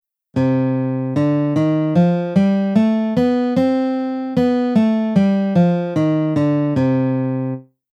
Gamme de Do mineure tièrcmineure do- mib
Exemple Do min
Gamme_Do_min.mp3